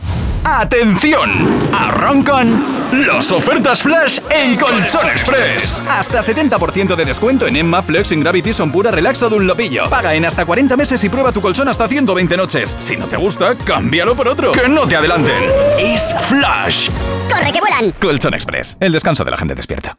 Cuña de radio para la cadena de colchonerías en Madrid